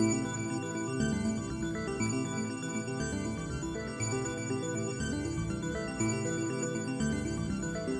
延迟吉他" 22 O1 31吉他1
描述：一包基于延迟的吉他声音，具有极简的悠闲感觉。
标签： 环境 气氛 电子 吉他 音乐 加工 节奏
声道立体声